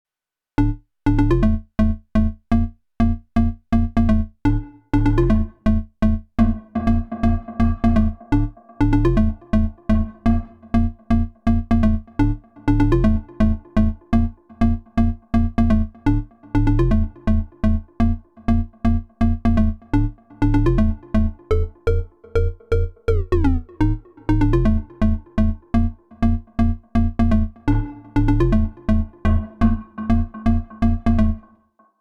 Organ Bass
Can you try one octave lower?
(maybe bit more tweaking required)